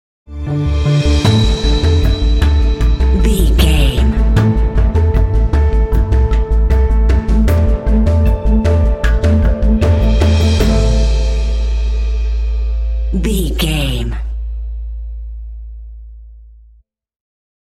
Aeolian/Minor
tension
suspense
dramatic
contemplative
drums
piano
strings
synthesiser
cinematic
film score